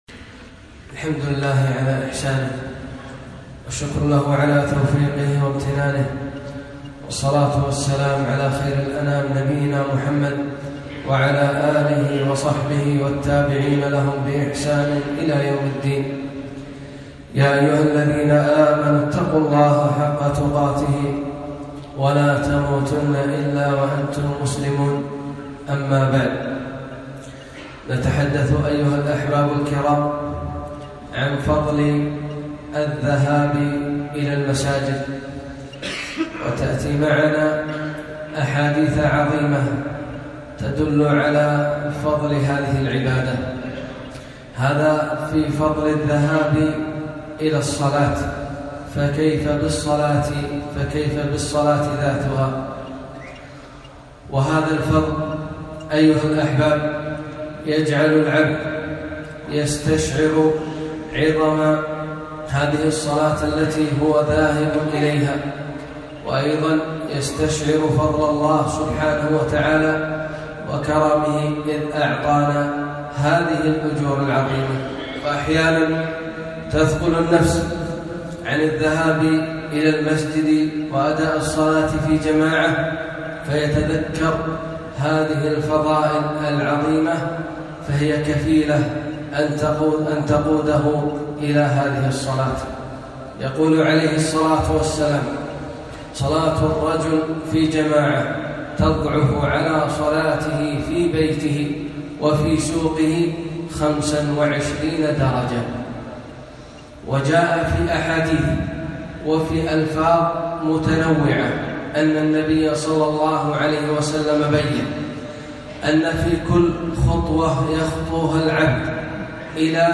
خطبة - فضل الذهاب إلى المساجد